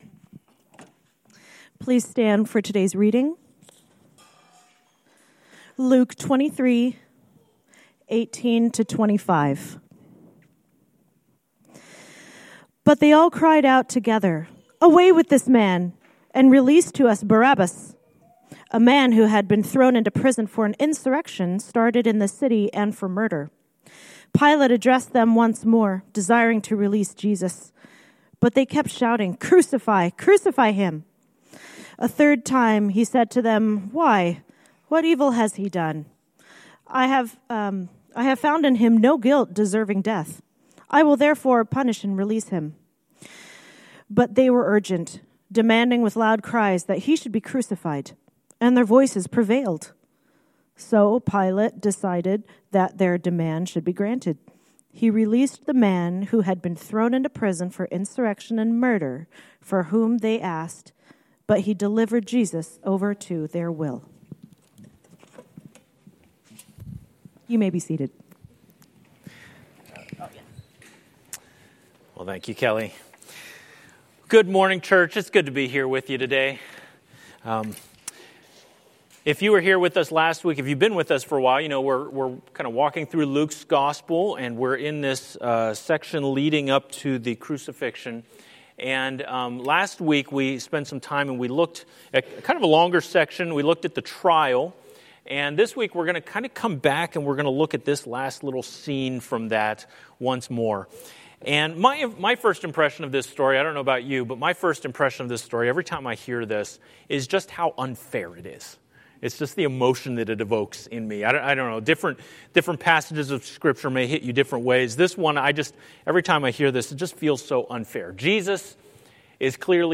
A message from the series "The Willing Sacrifice."